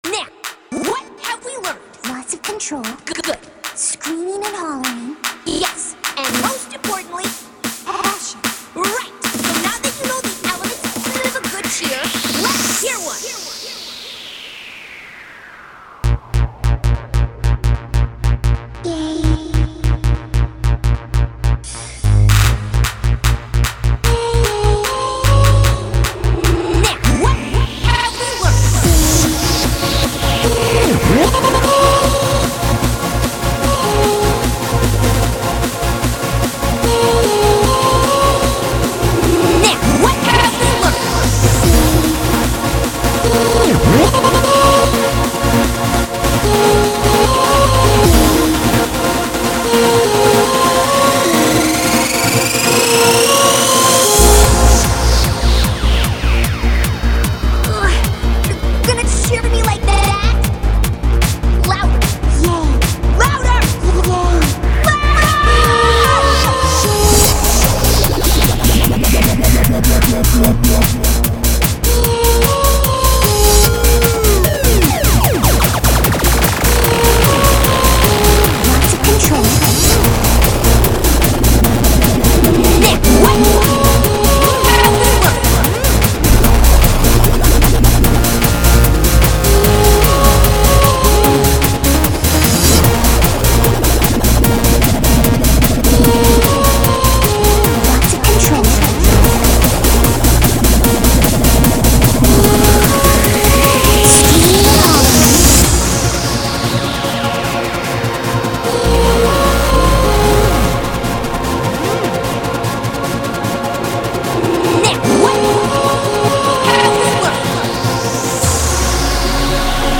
genre:remix